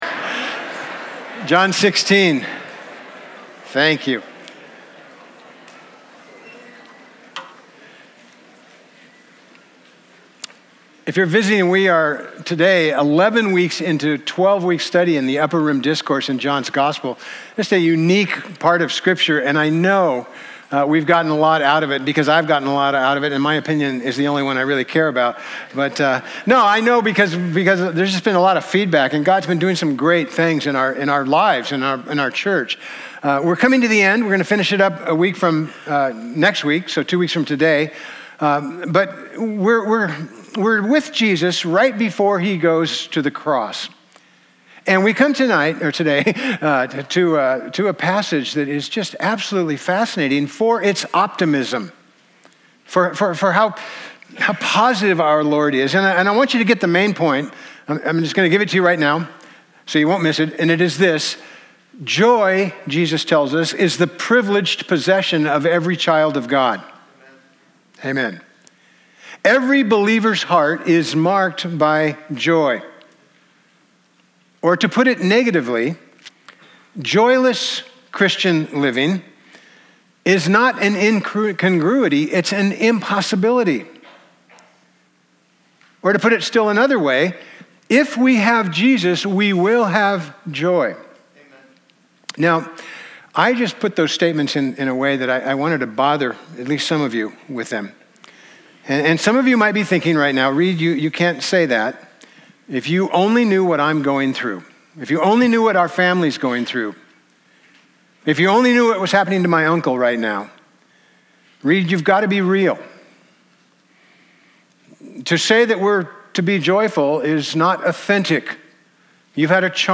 John 16:16-24 Service Type: Sunday Topics